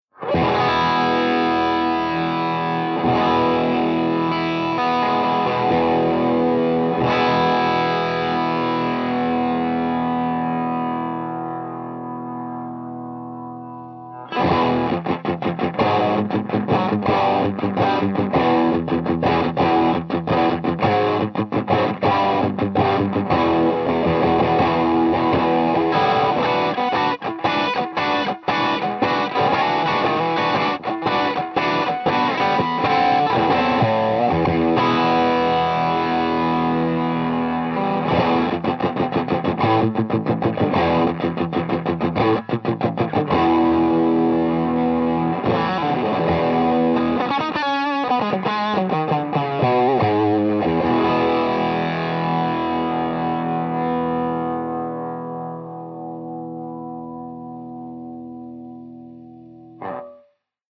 023_BUCKINGHAM_FUZZ_HB.mp3